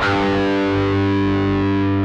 GUITAR.WAV